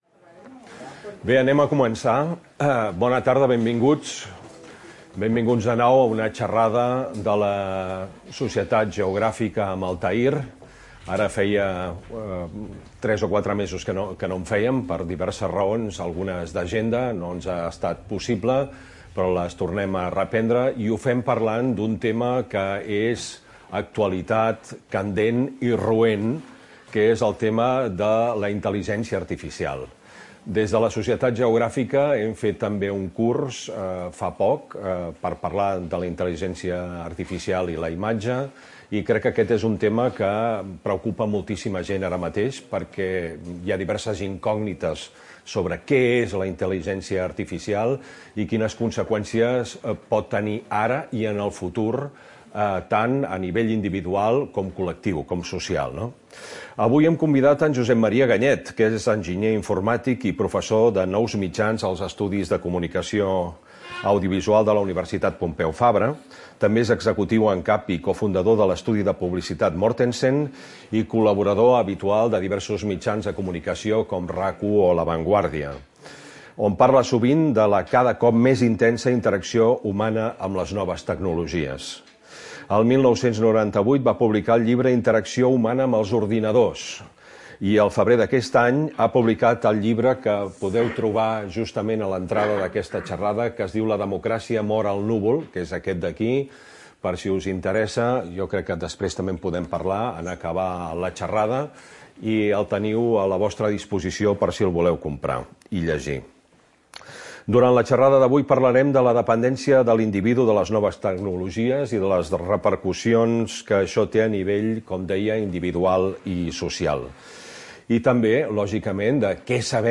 xerrada